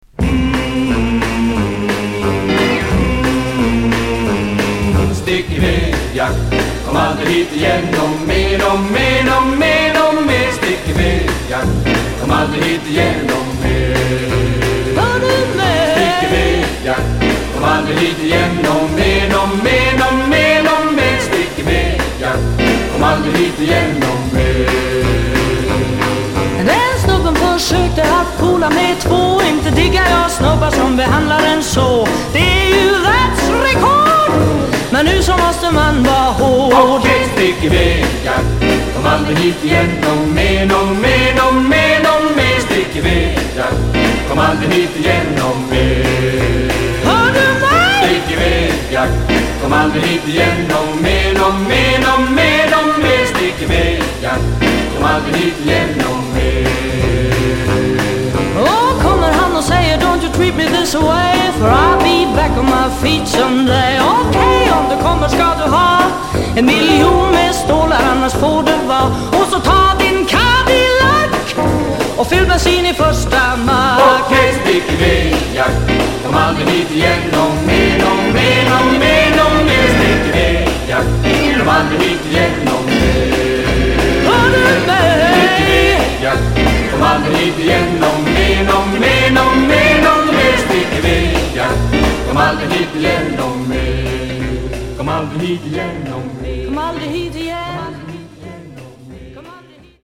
Jazz Vocal sweden
スウェーデンの女性シンガー